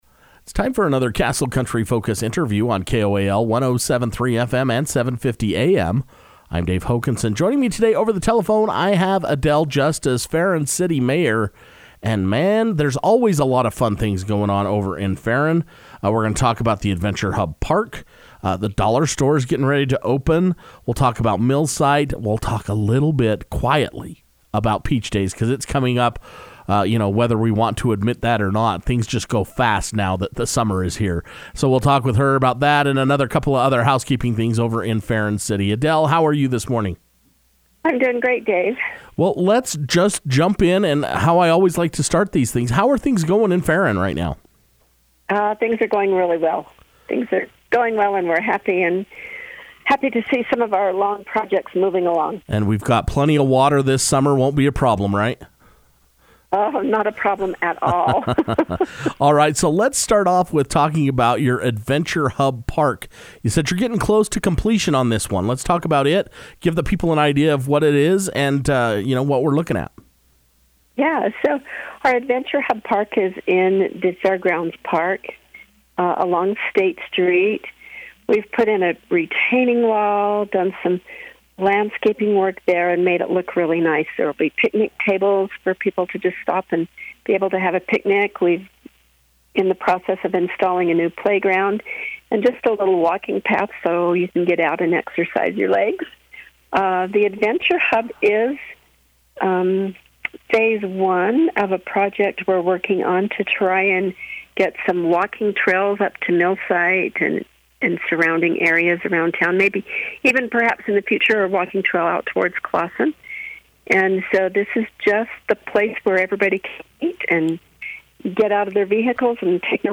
Now that school is out, the weather is warming up and Ferron City is looking to finish up its Adventure Hub Park and relocate the city dumpsters. Castle Country Radio was able to speak over the telephone with Mayor Adele Justice to get all the details.